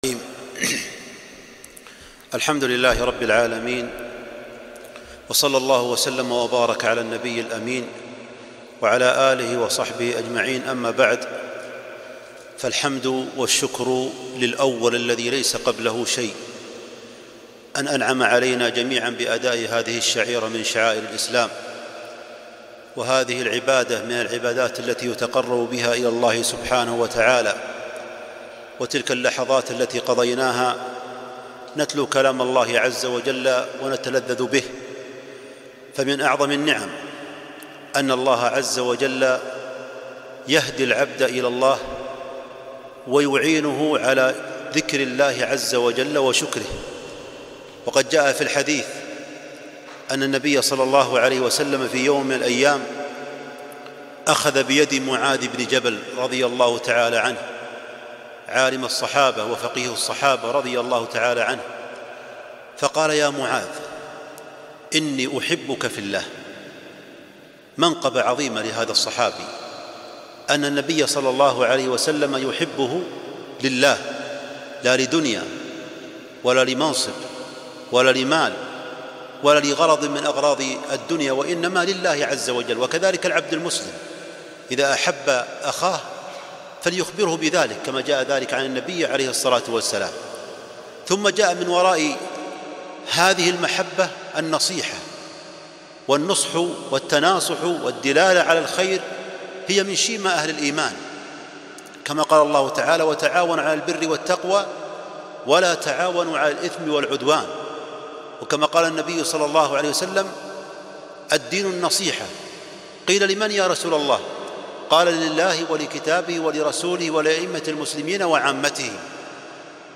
كلمة - من أعظم نعم الله على العبد الهداية إلى الطاعة والعون عليها